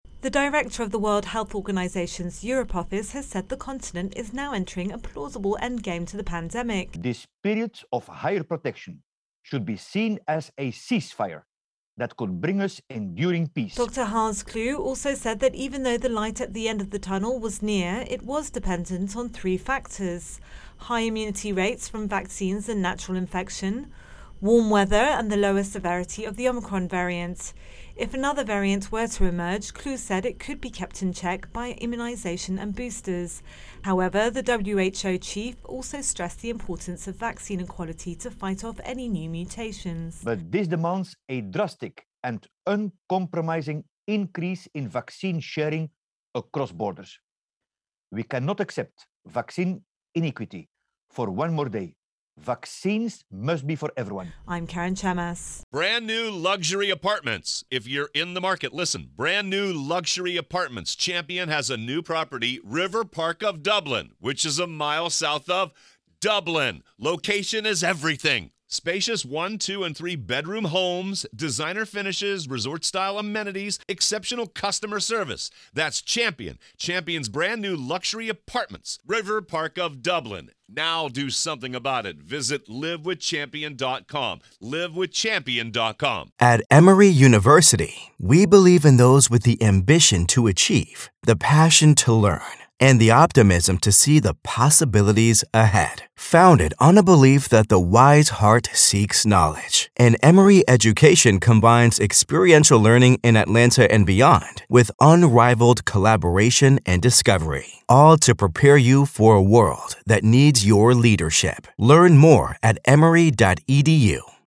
Intro and voicer on Virus Outbreak WHO Europe.